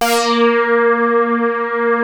P.5 A#4 1.wav